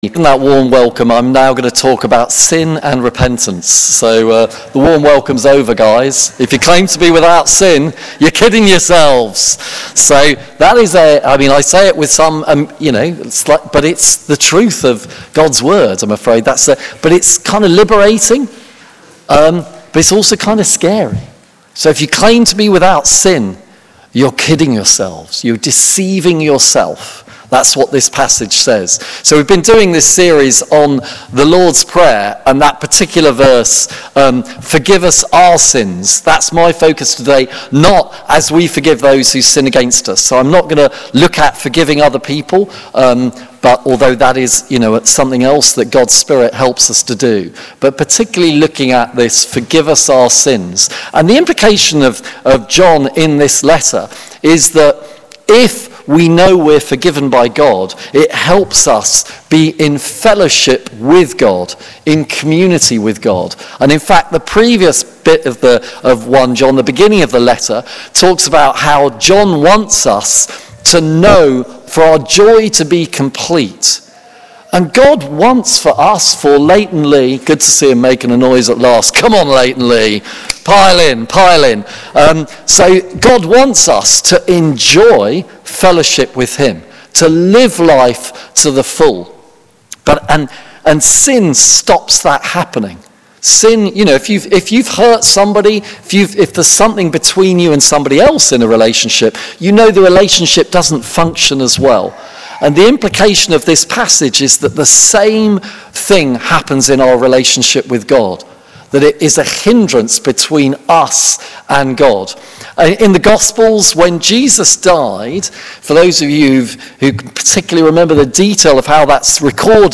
Listen to our 9.30am and 11.15am sermon here:
Service Type: Worship Together